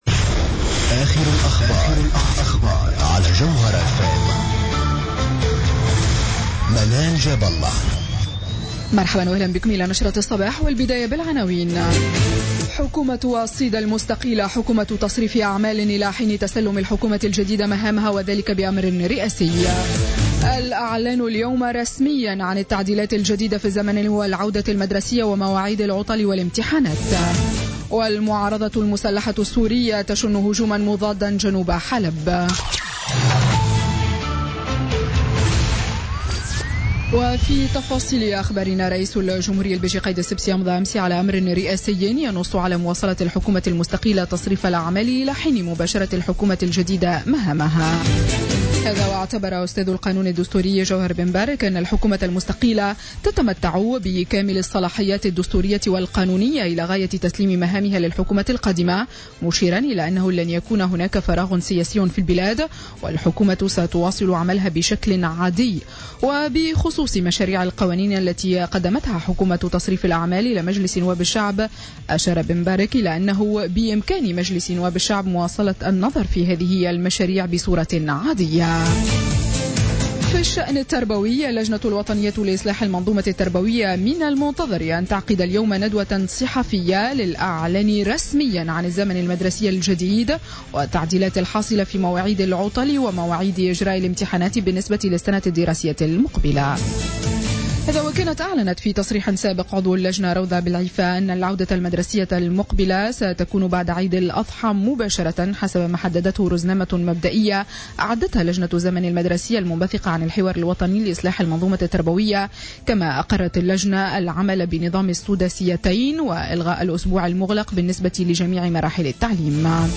Journal Info 07h00 du lundi 1er août 2016